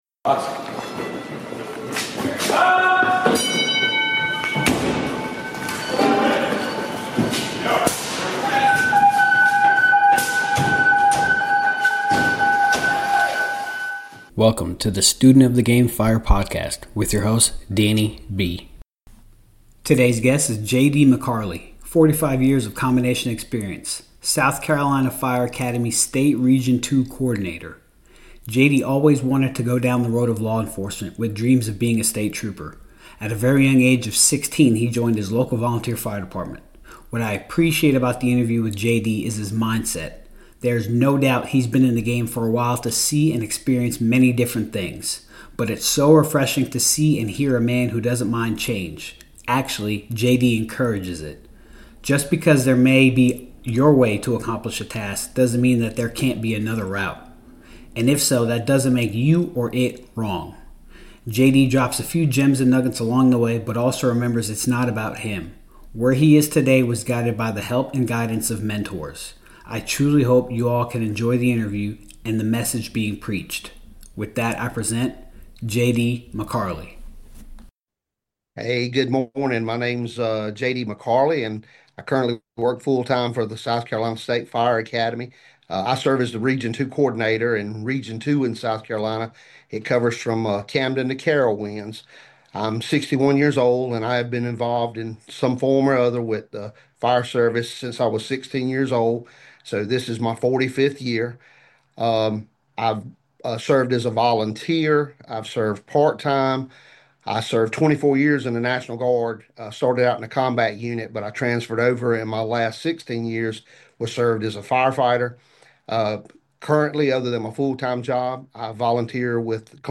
I truly hope you all can enjoy the interview and the message being preached.